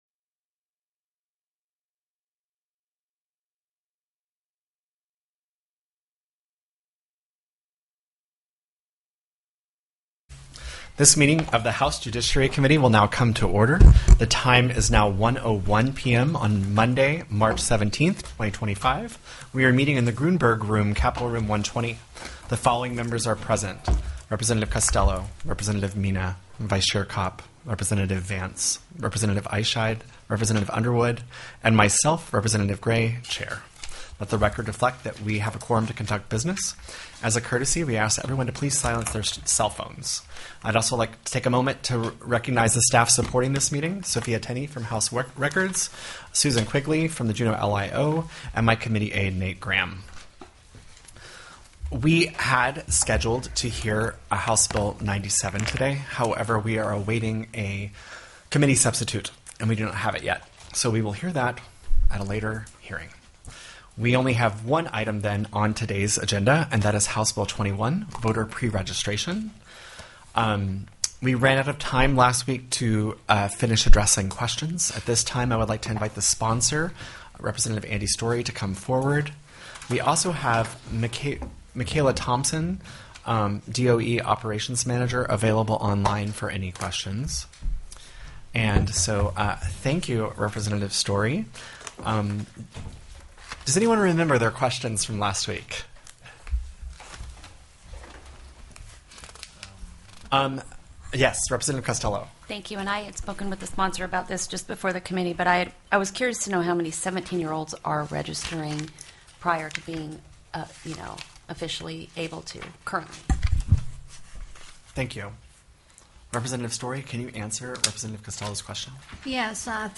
03/17/2025 01:00 PM House JUDICIARY
The audio recordings are captured by our records offices as the official record of the meeting and will have more accurate timestamps.
+= HB 21 VOTER PREREGISTRATION FOR MINORS TELECONFERENCED
CHAIR GRAY invited  the bill sponsor to give  closing comments on
REPRESENTATIVE  KOPP   moved  to  report  CSHB   21(STA)  out  of